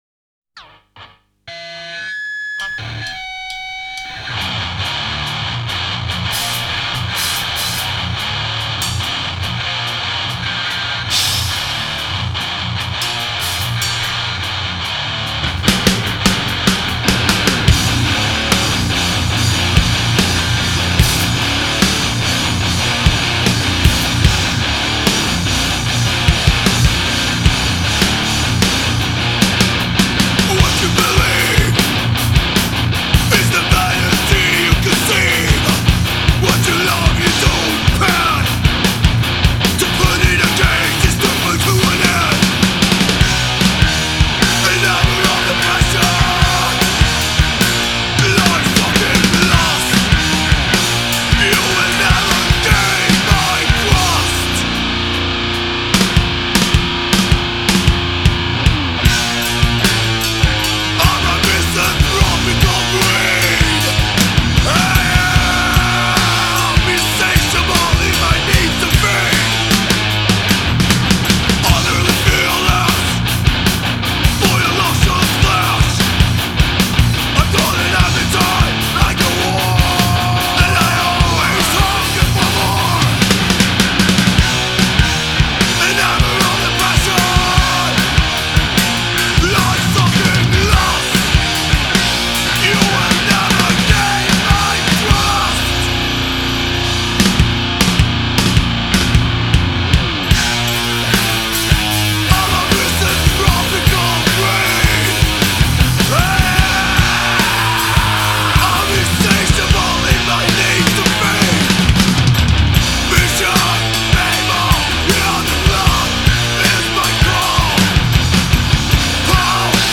Death n roll
دث متال